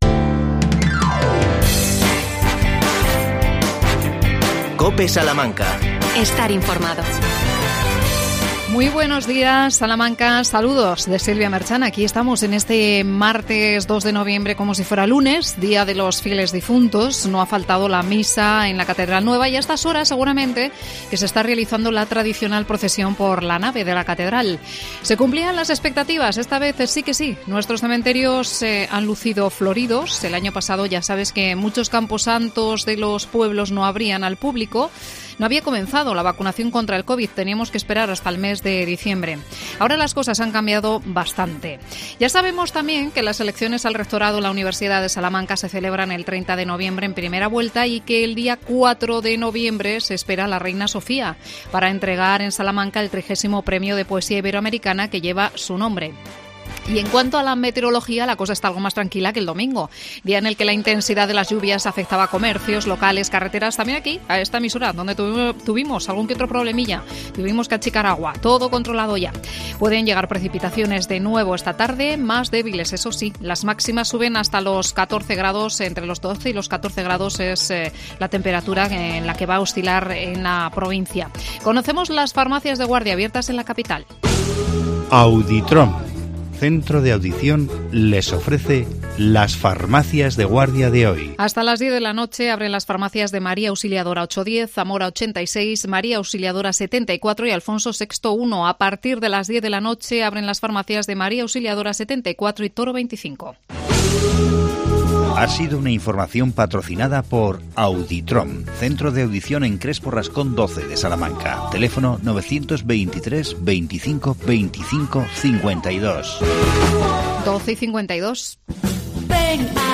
AUDIO: La concejala de Salud Pública Mariajosé Fresnadillo nos presenta la Semana de las Legumbres.